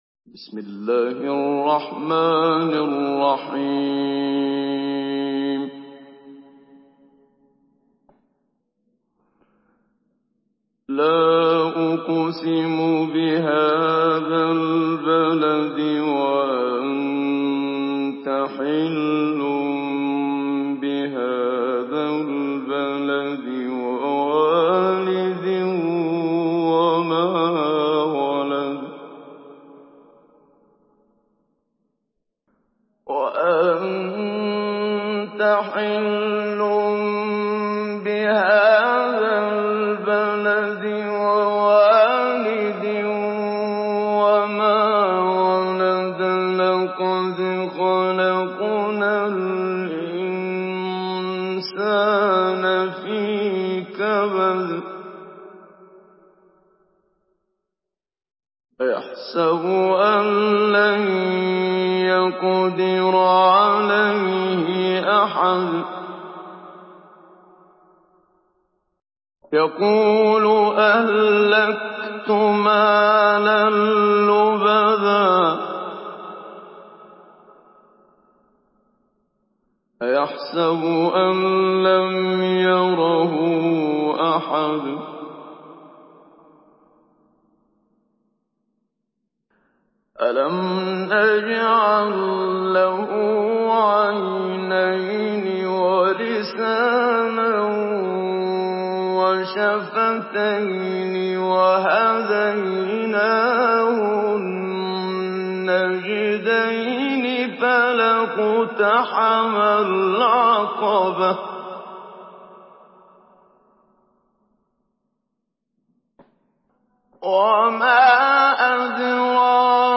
Surah আল-বালাদ MP3 by Muhammad Siddiq Minshawi Mujawwad in Hafs An Asim narration.